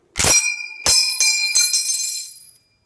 ping_4tjei1u-1.mp3